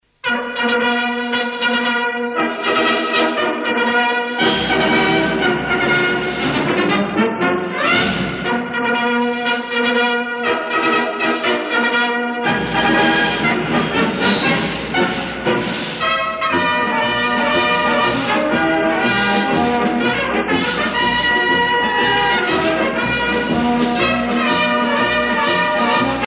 Marches militaires internationales
Pièce musicale éditée